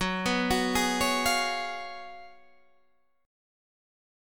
GbM7sus2sus4 chord